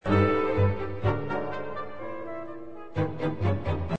حال و هوای موسیقی با تقلیدی از عبارت آغازین “Yankee Doodle” که به چالاکی با ترومپت، فلوت و ویولن ها نواخته می شود باز هم مضحک تر می شود.